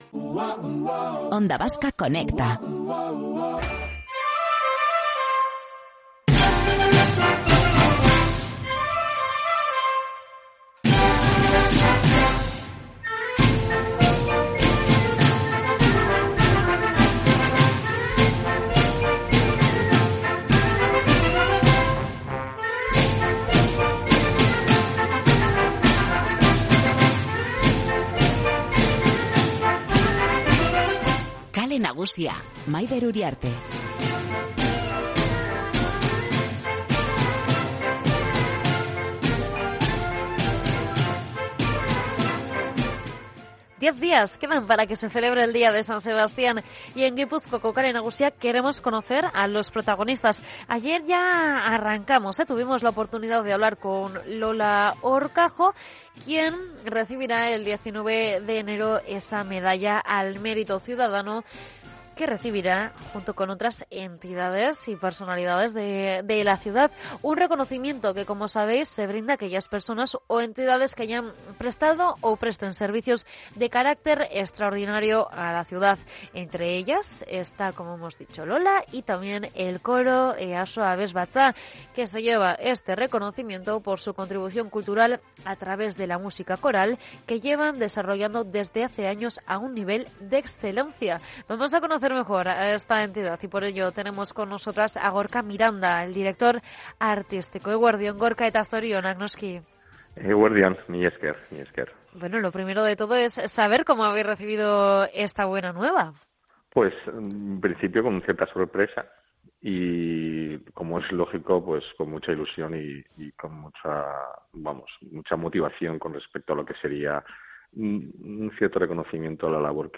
En Gipuzkoako Kale Nagusia seguimos con la serie de entrevistas a las y los protagonistas de la Tamborrada donostiarra y la primera entidad que hemos conocido...